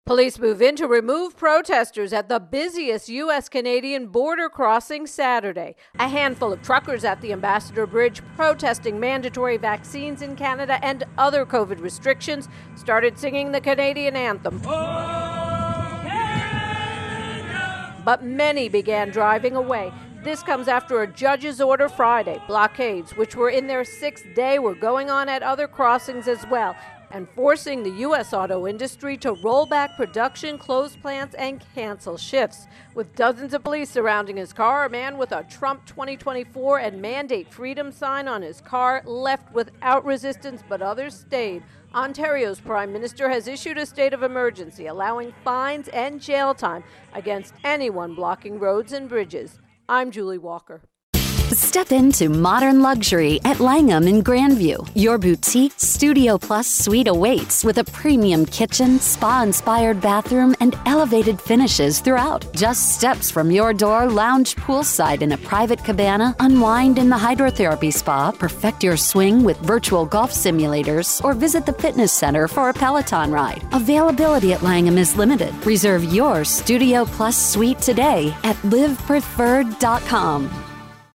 Virus Outbreak Protests intro and voicer